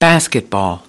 9. basketball  /ˈbæs.kət.bɑːl/ : bóng rổ.